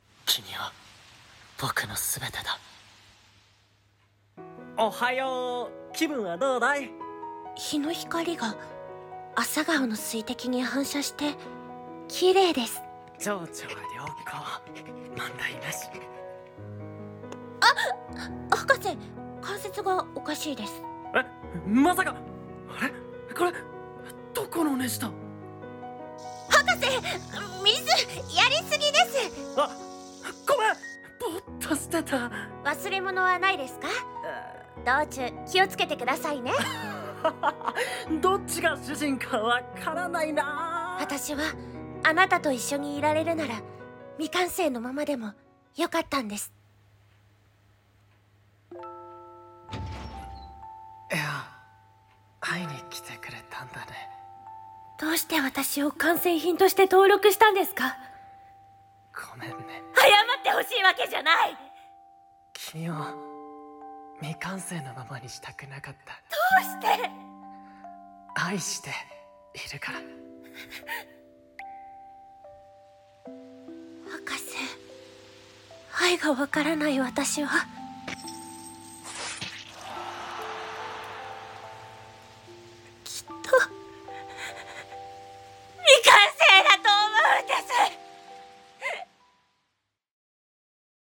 【2人声劇】完成罪